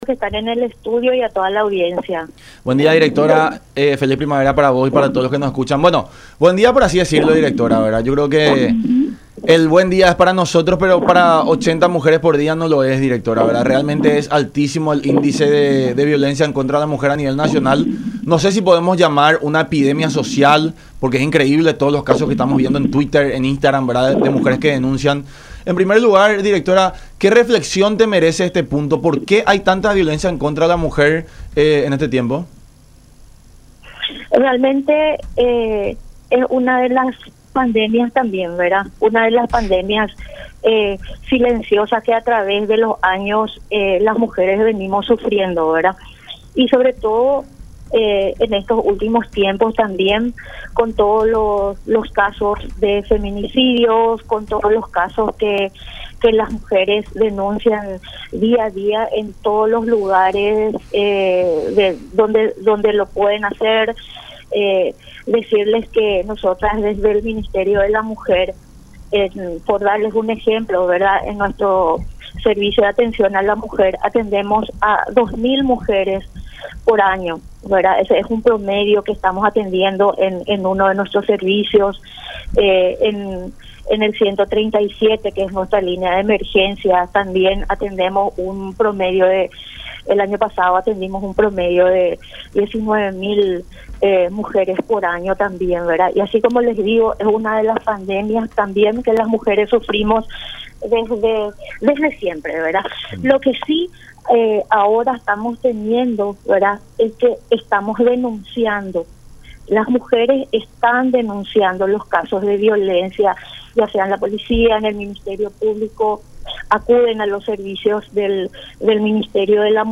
Lo que sí ahora las mujeres están denunciando en las comisarías, en el Ministerio Público”, dijo Ana Pavón, directora general contra toda forma de violencia del Ministerio de la Mujer, en charla con La Unión Hace La Fuerza por Unión TV y radio La Unión, estimando que las cifras aumenten para finales de este año.